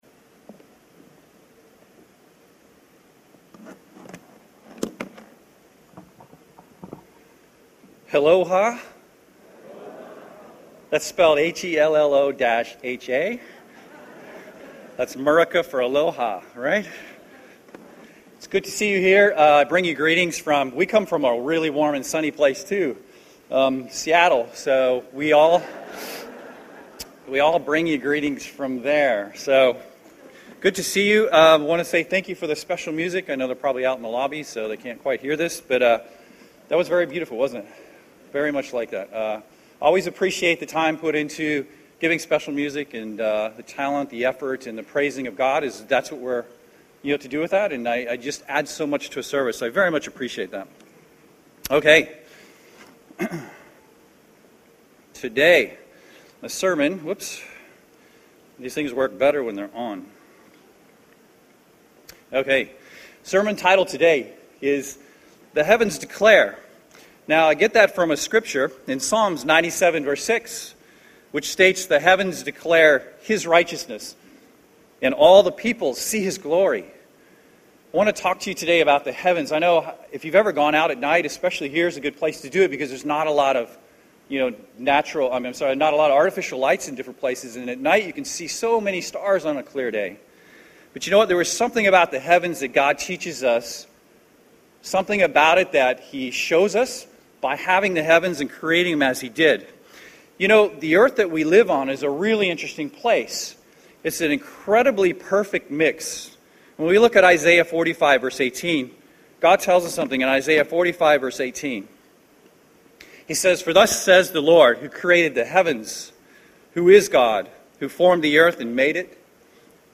This sermon was given at the Maui, Hawaii 2015 Feast site.